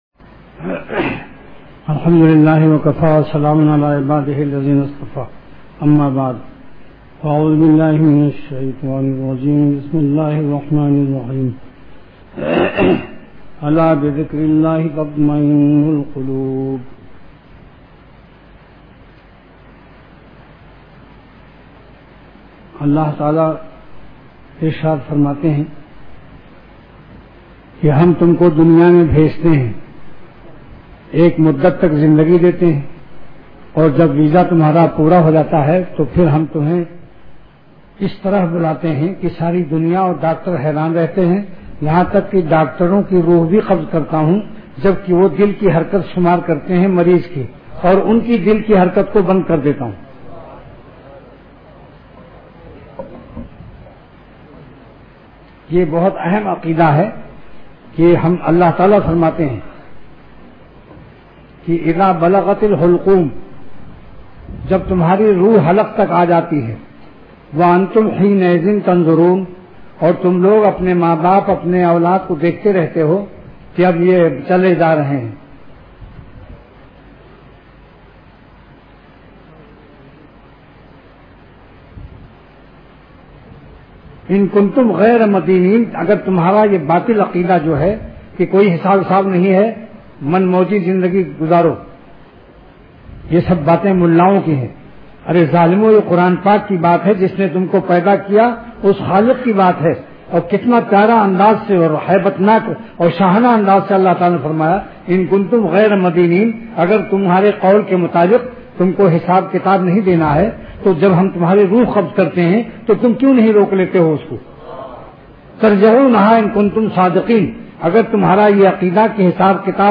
Delivered at Khanqah Imdadia Ashrafia.